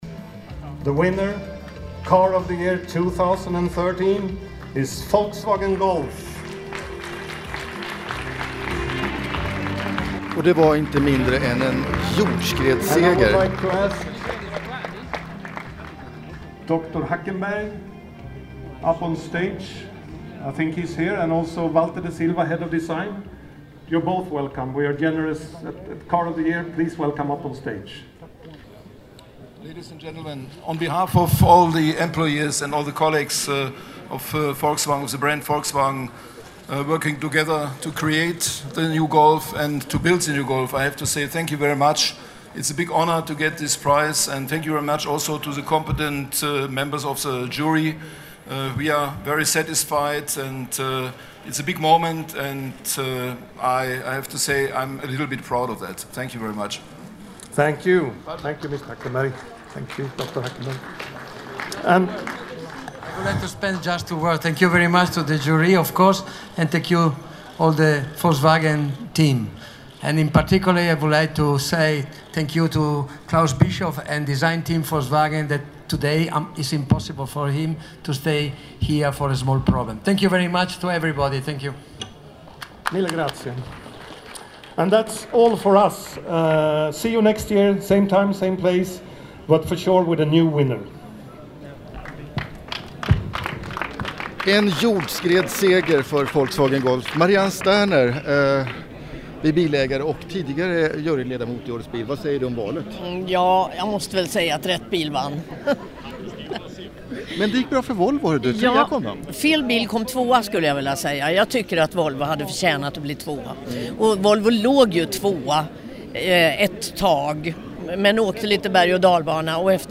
geneve_arets_bil_2013.mp3